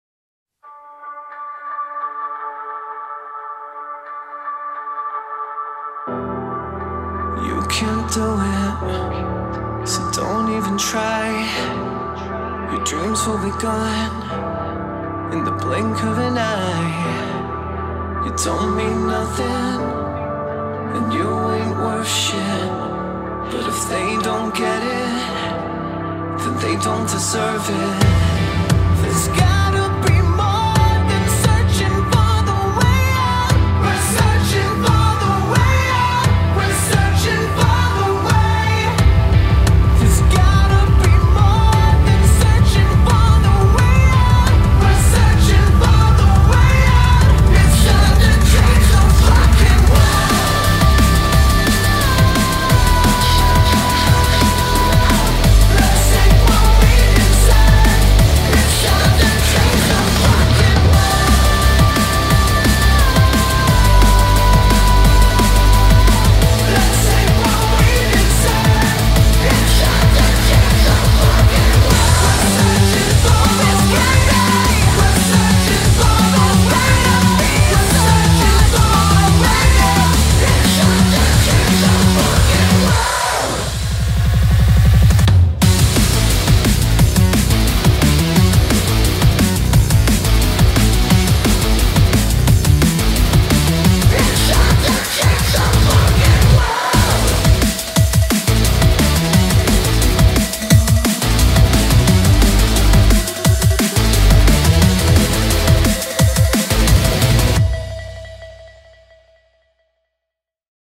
BPM88-175
Audio QualityMusic Cut